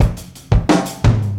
Soho Groove 87.3bpm.wav